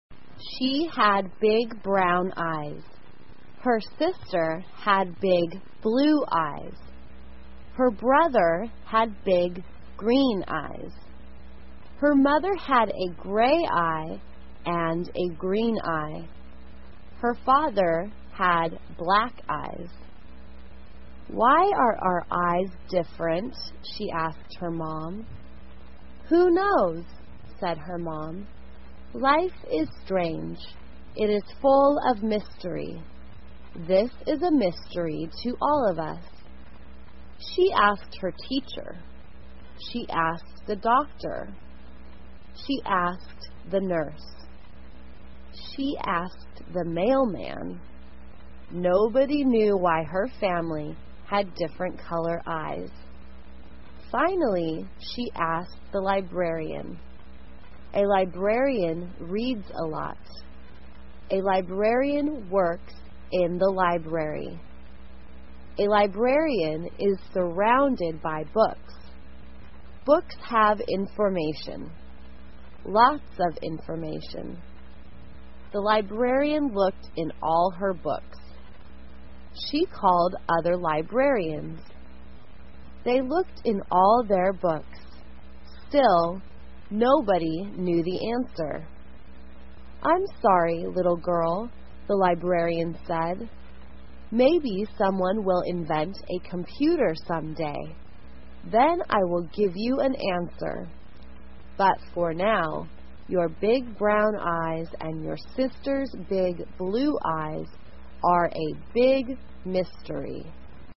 慢速英语短文听力 棕色和蓝色的眼睛 听力文件下载—在线英语听力室